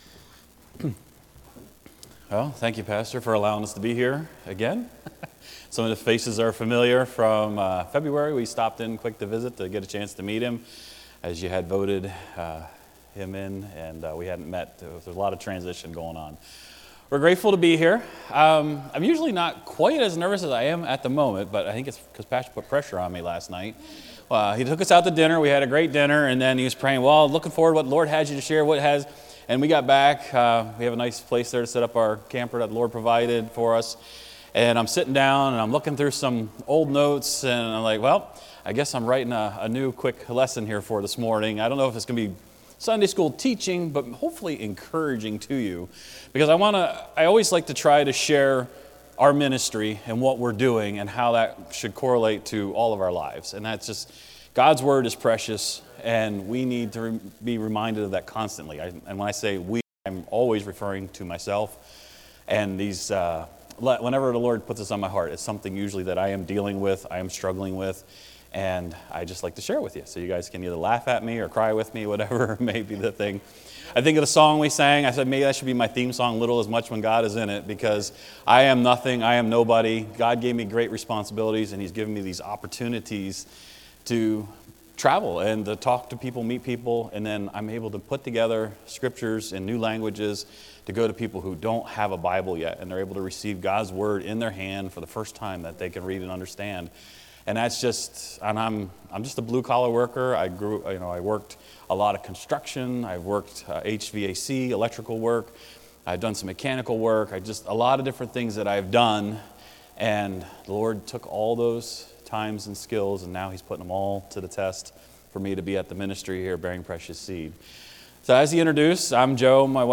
Guest Preacher